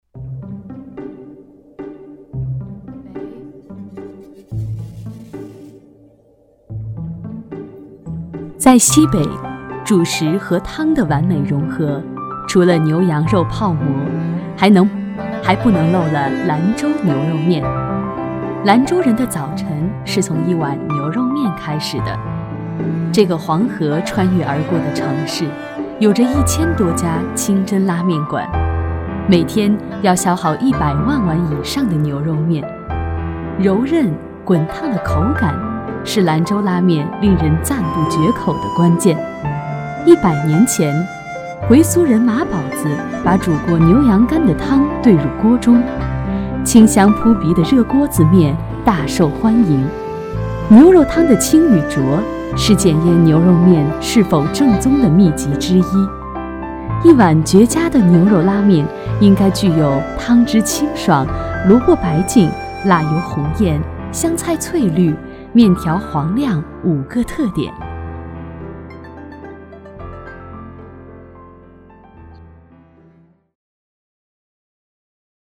女国95_纪录片_舌尖_兰州牛肉面.mp3